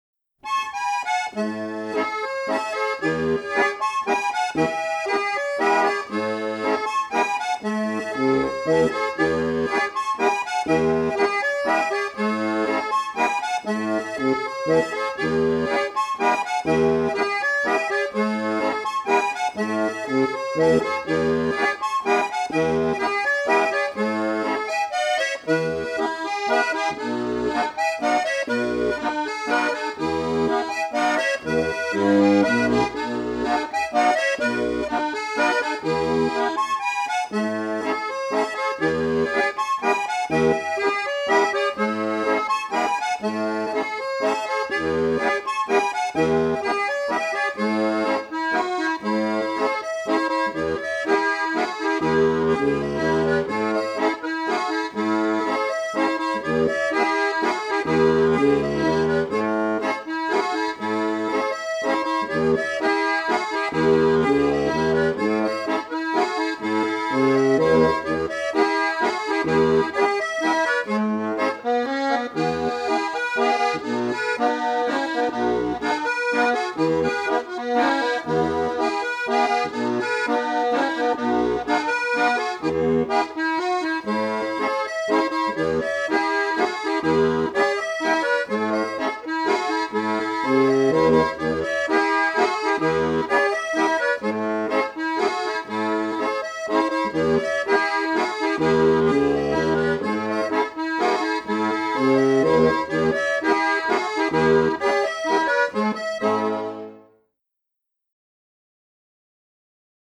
Jodler, Jodler-Lied, Gstanzl und Tanz
1850–2019; the Lower Austrian and Styrian part of the Wechsel-region as well as the adjoining areas (Bucklige Welt, Lower Austria; Joglland, Styria)
Folk & traditional music